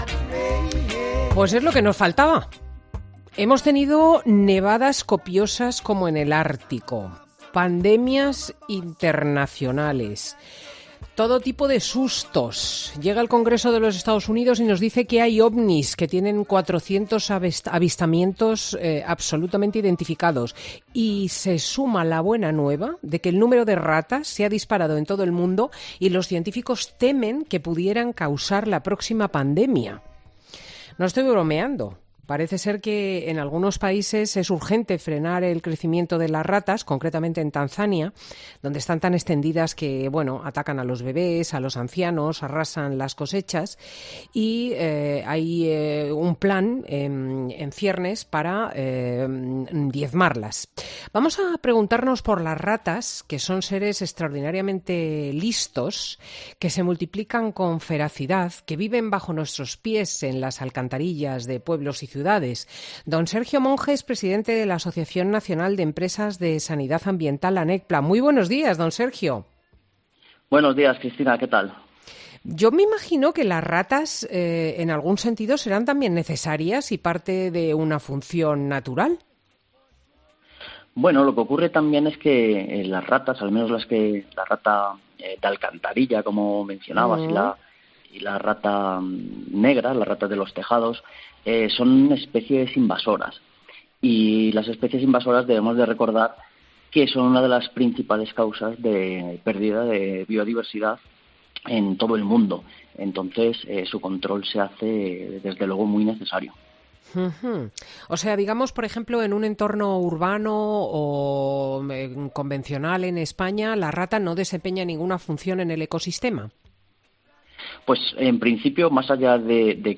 El experto explica en 'Fin de Semana COPE' la dificultad que existe a la hora de matar a este tipo de animales y el riesgo de sus plagas